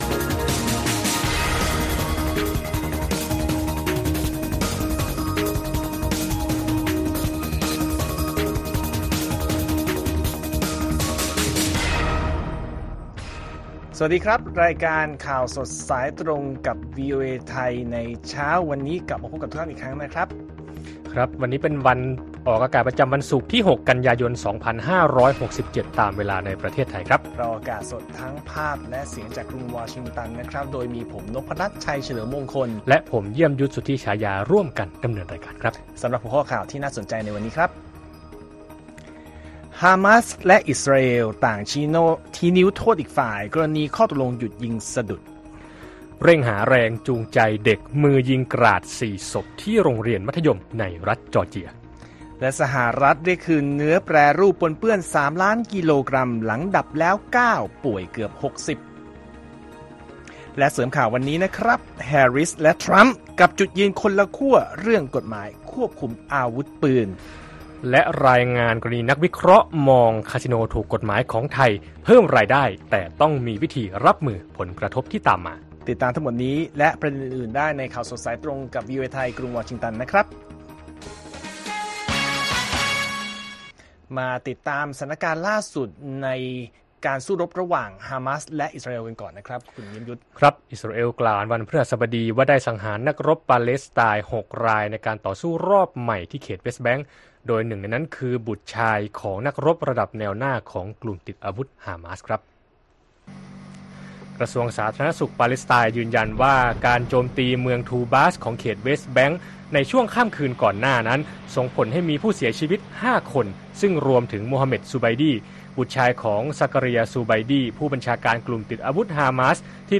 ข่าวสดสายตรงจากวีโอเอ ไทย ประจำวันศุกร์ที่ 6 กันยายน 2567